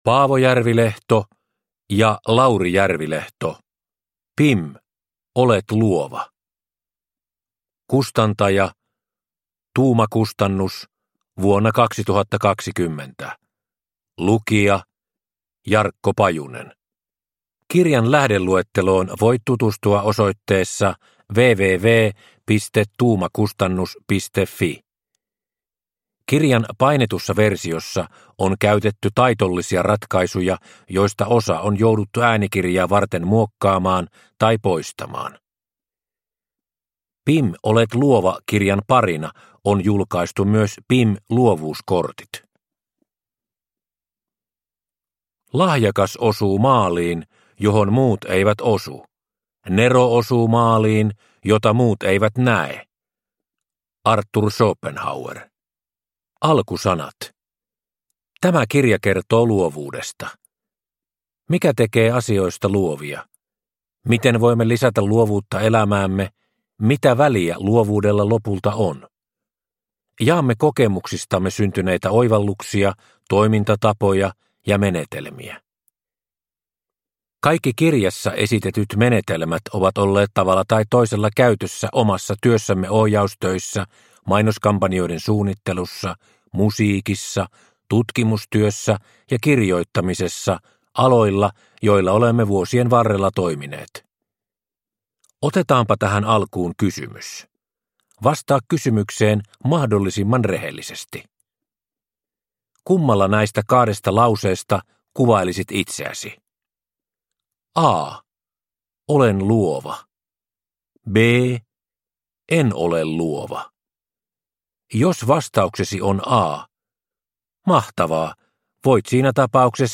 Pim! Olet luova – Ljudbok – Laddas ner